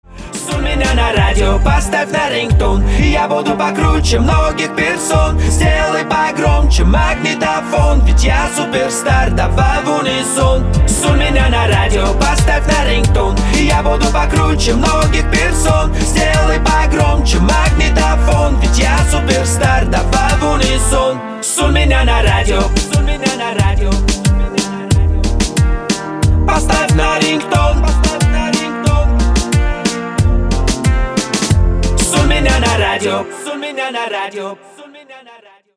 поп
мужской вокал
веселые
dance
шуточные